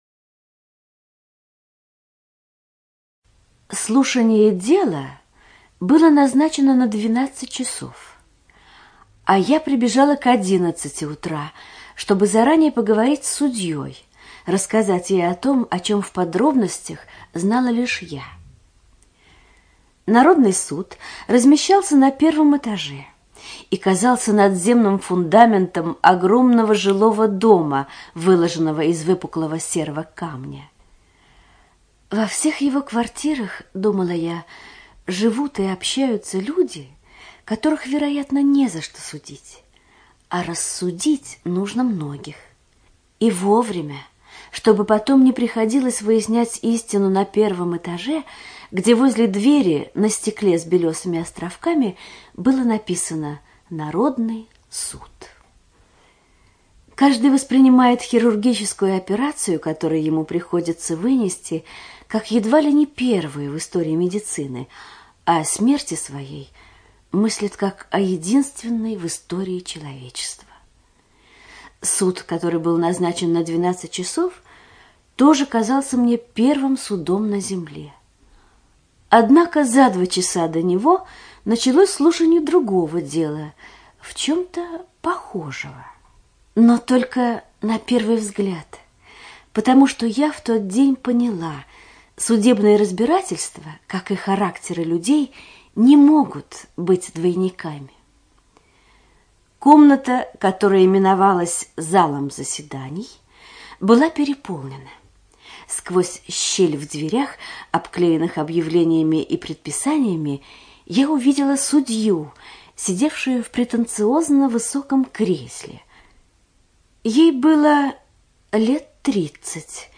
ЖанрСовременная проза